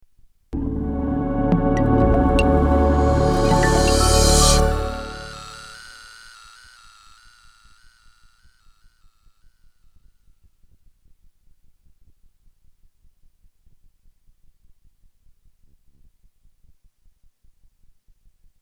Sound Logo
サウンドロゴ